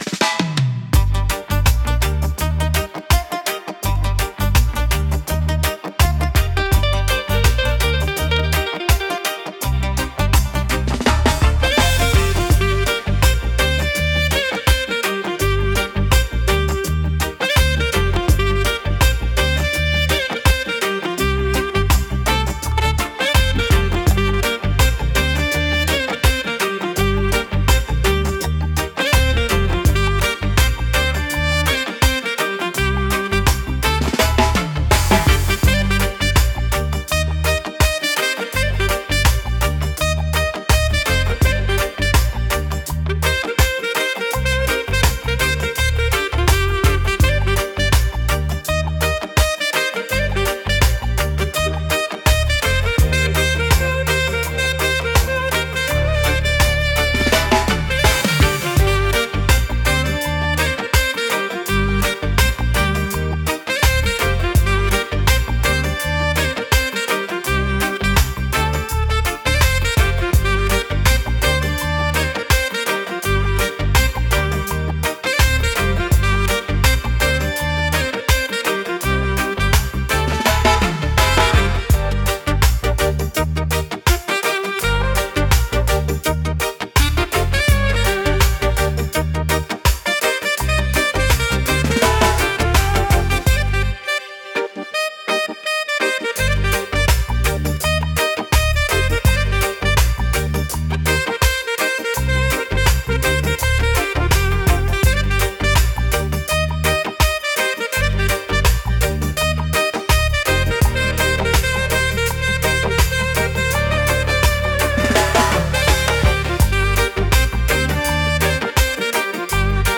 レゲエ特有のベースラインとドラムパターンが心地よいグルーヴを生み出します。
ナチュラルで陽気な雰囲気を演出し、聴く人に穏やかで楽しい気分をもたらします。